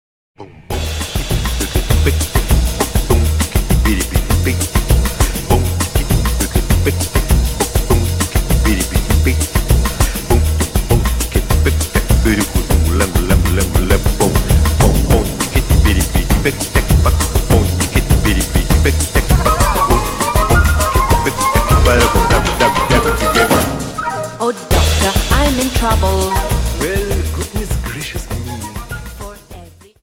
Dance: Samba 50